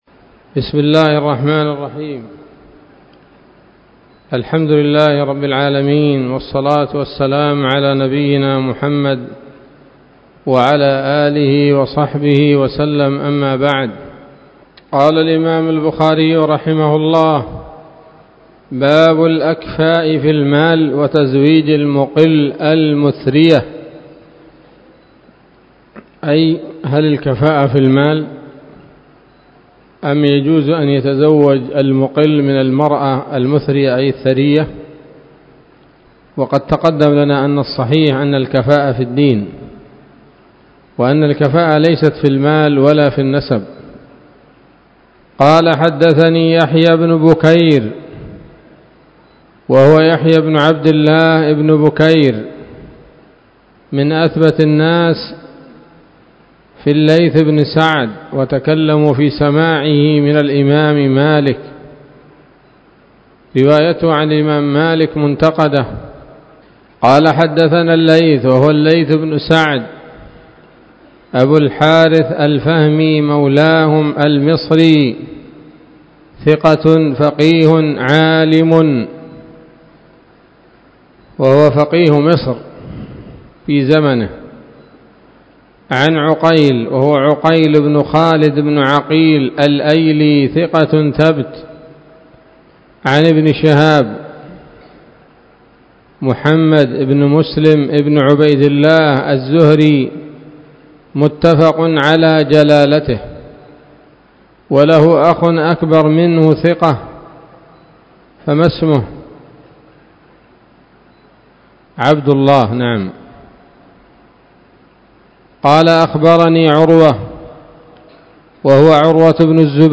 الدرس السادس عشر من كتاب النكاح من صحيح الإمام البخاري